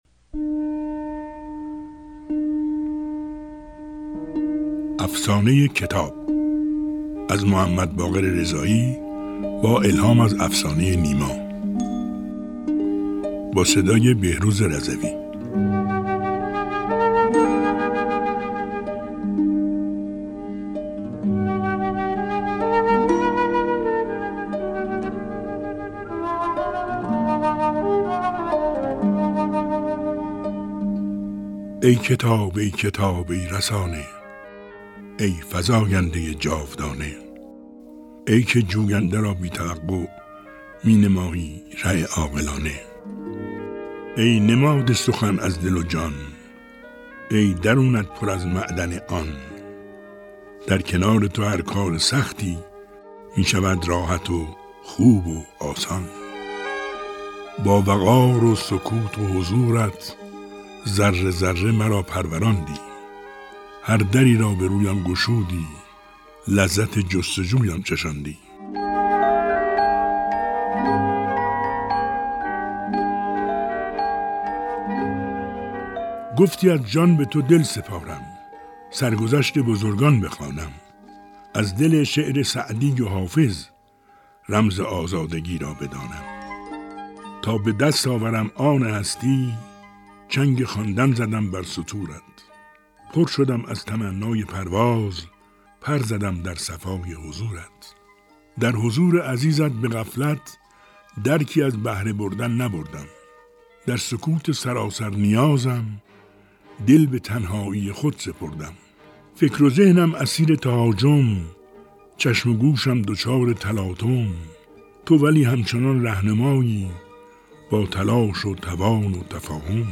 شعری از محمدباقر رضایی با صدای بهروز رضوی
به گزارش ایکنا؛ محمدباقر رضایی؛ نویسنده و شاعر کشور و سردبیر برنامه کتاب شب، هم‌زمان با سی‌ودومین هفته کتاب جمهوری اسلامی ایران؛ قطعه شعری با موضوع کتاب سروده و این سروده را بهروز رضوی؛ گوینده پیشکسوت قرائت کرده و حاصل این کار هنری ادبی و هنری را در اختیار مخاطبان ایکنا قرار داده‌ است.